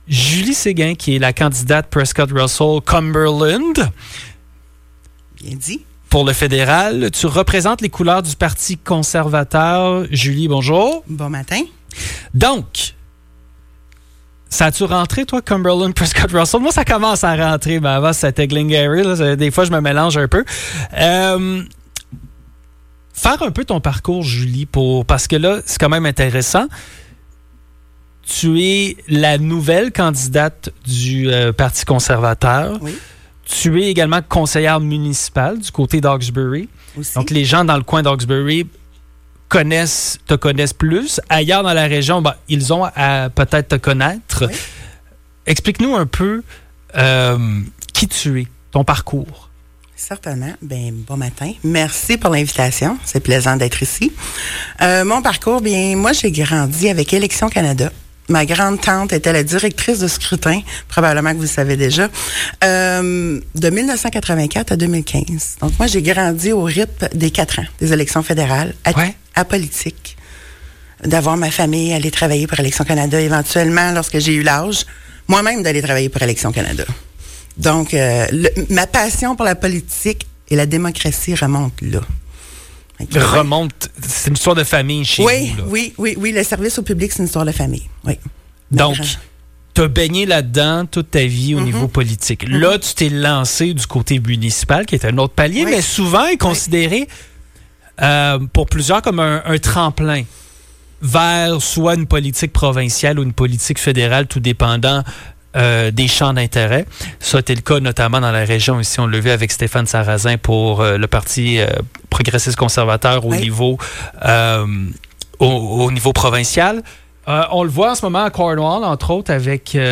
était de passage en studio.